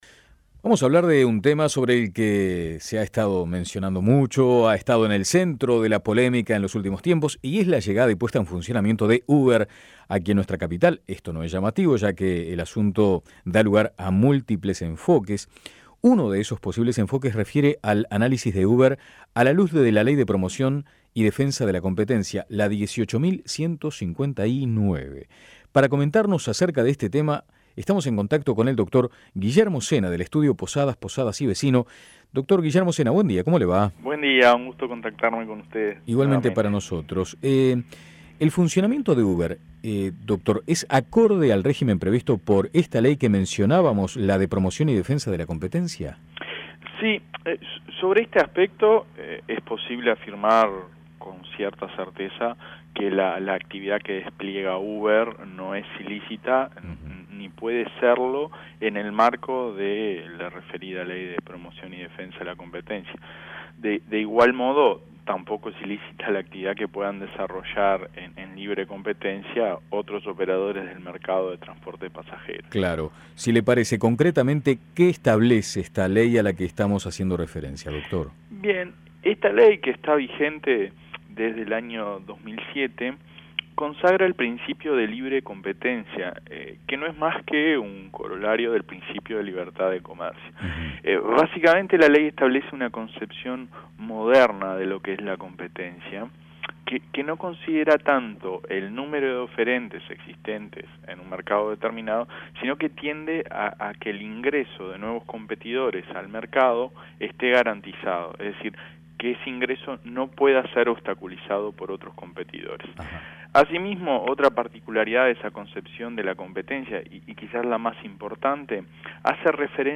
Análisis Posadas, Posadas y Vecino